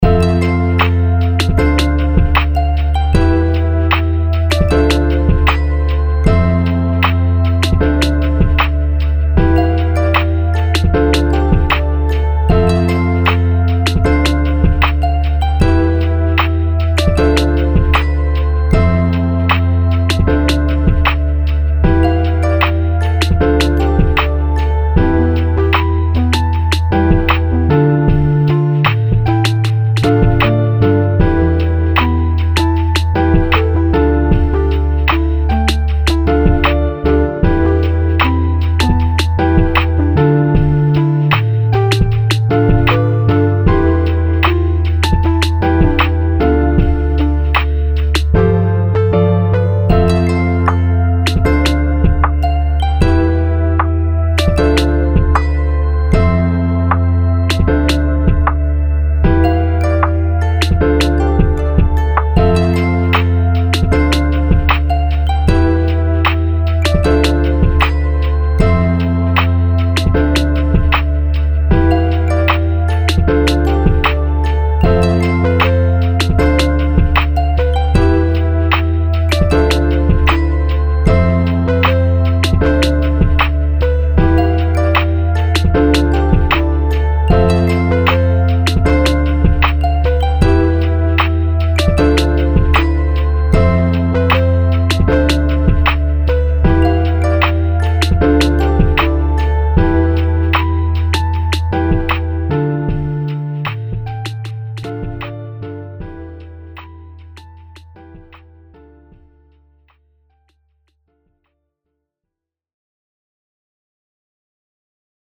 おしゃれ しっとり まったり